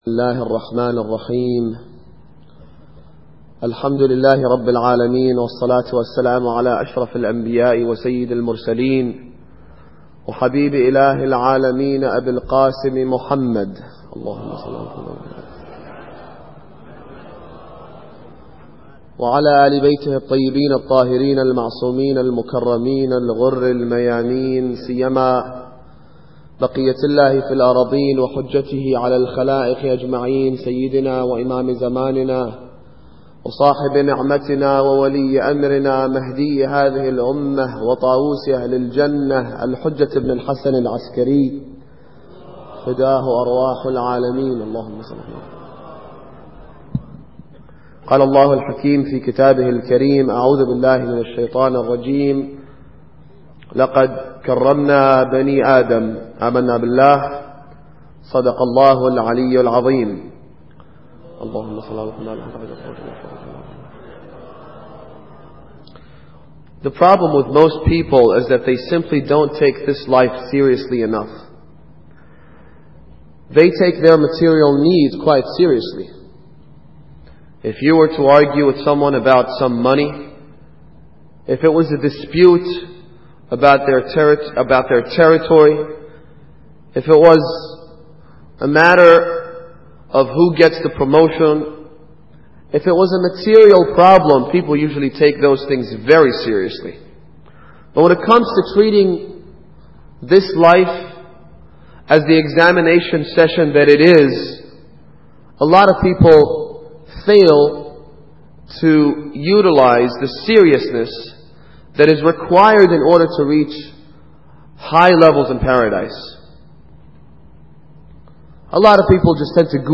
Muharram Lecture 4